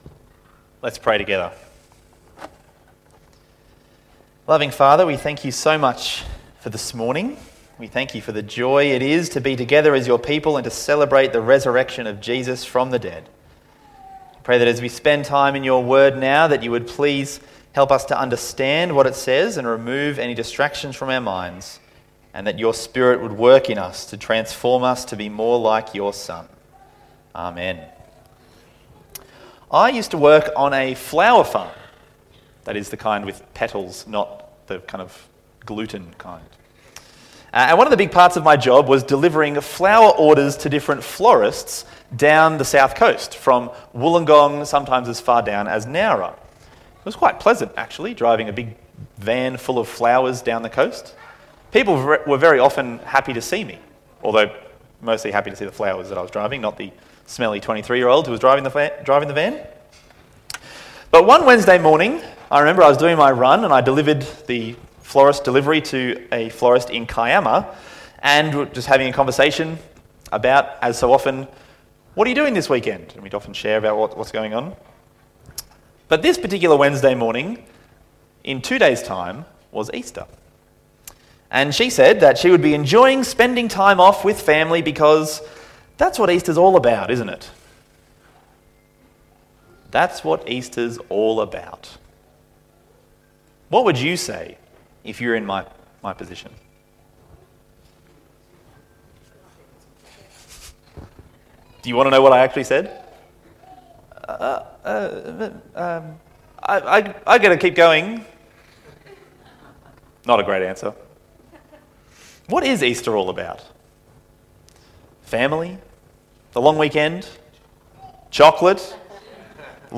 A new sermon is available: